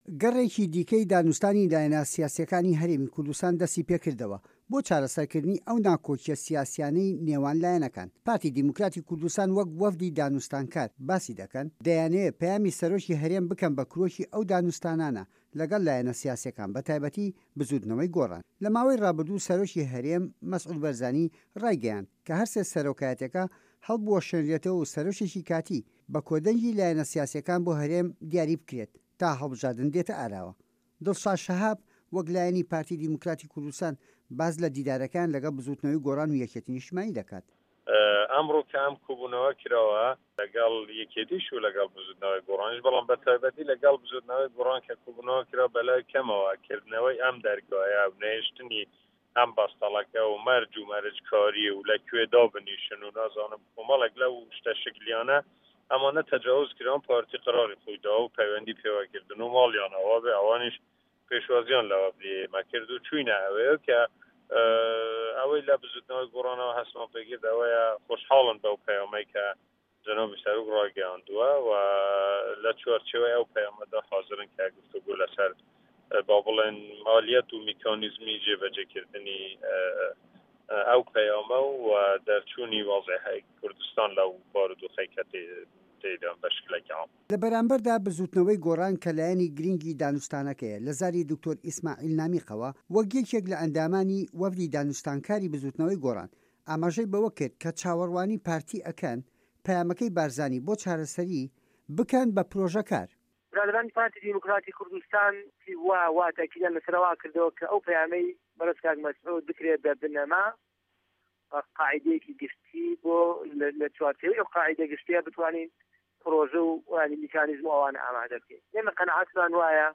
له‌ به‌رامبه‌ردا لایه‌نه‌کان داوا ده‌که‌ن ئه‌و نامه‌یه‌ی بارزانی بکه‌نه‌ پرۆژه‌ی سیاسی ، ئه‌وسا ئه‌مانیش وه‌لامی خۆیان ده‌ده‌نه‌وه‌، له‌م راپۆرته‌ رای نوێنه‌ری پارتی و بزوتنه‌وه‌ی گۆڕان و یه‌کگرتووی ئیسلامی وه‌رگیراوه‌.